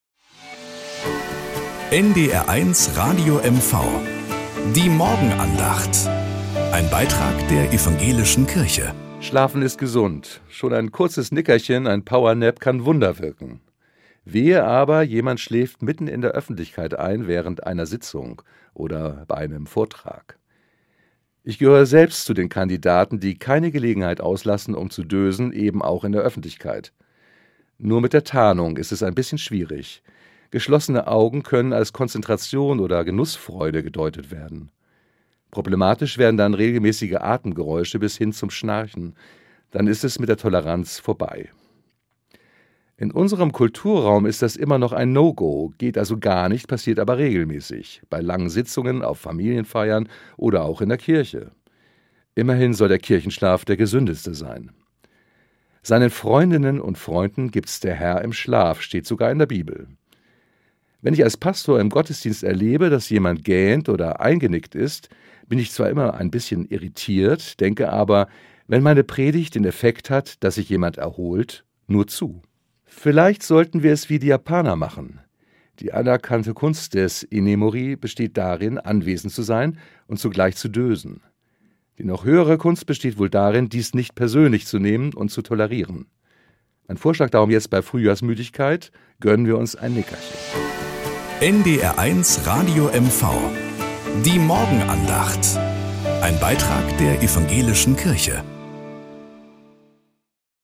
Nachrichten aus Mecklenburg-Vorpommern - 28.02.2025